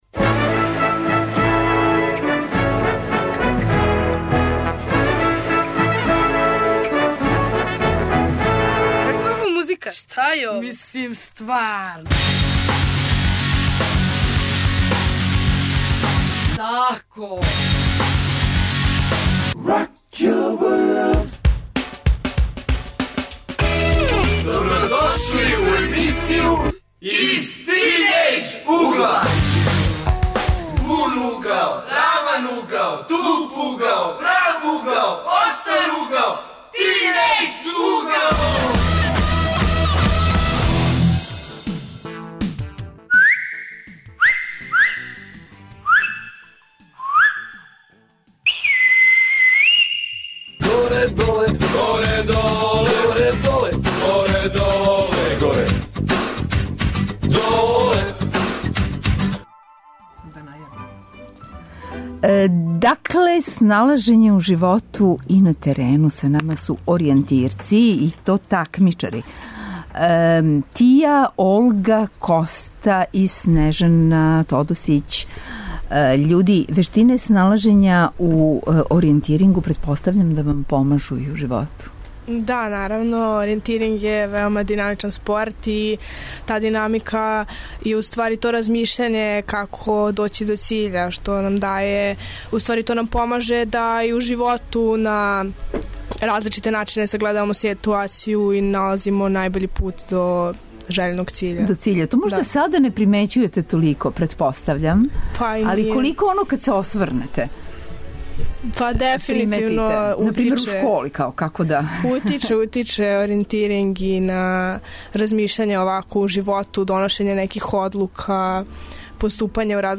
Тема: сналажење у простору и животу. Гости - такмичари оријентирци. Укључићемо се и у школу оријетиринга која је тренутно у току.